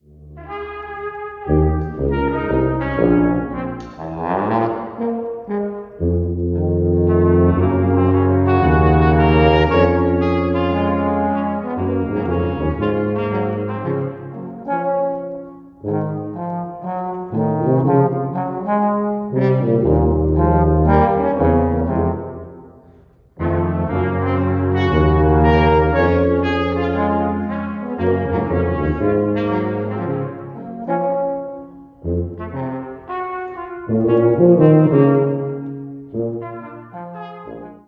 Brass Quintet
Catching Our Breath (2 Bb trumpets, F Horn, Trombone, Tuba)
When your ensemble needs to just hang out in middle range… not too loud… with lots of rests.